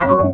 Key-organ-03_002.wav